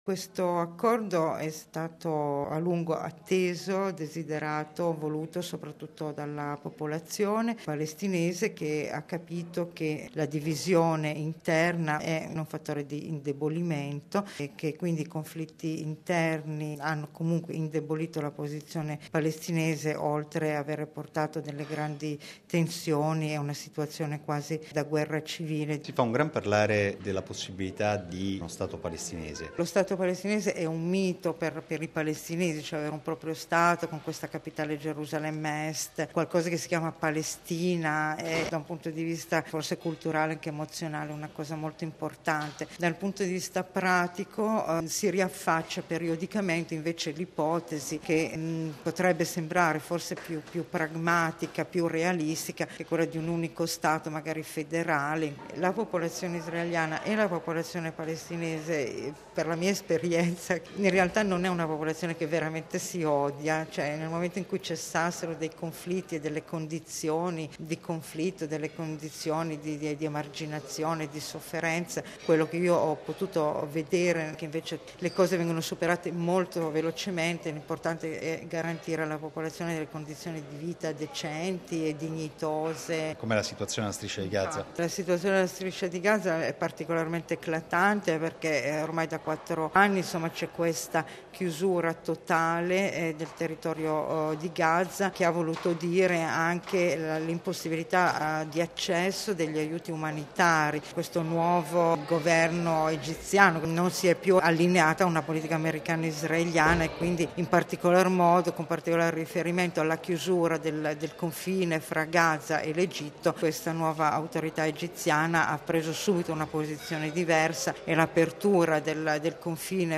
D. – Si fa un gran parlare della possibilità di uno Stato palestinese...